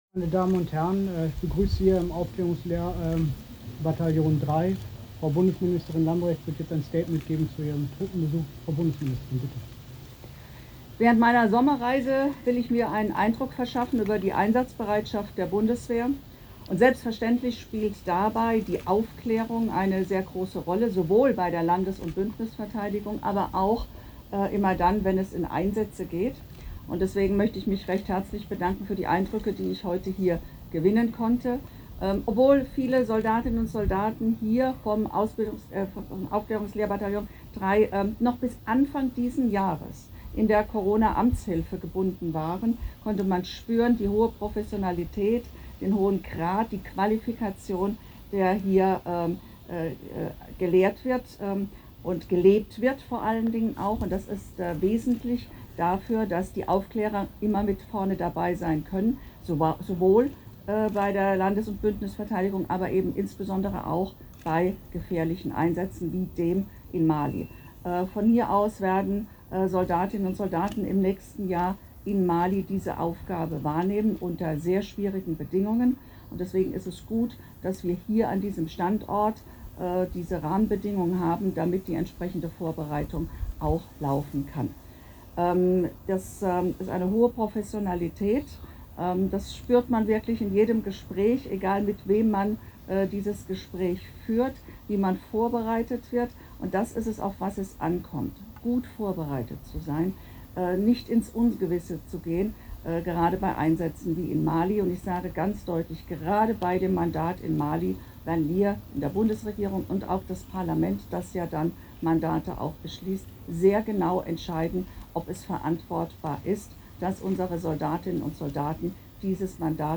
Bei manchen Mobilgeräten und Browsern funktioniert die Sprachausgabe nicht korrekt, sodass wir Ihnen diese Funktion leider nicht anbieten können.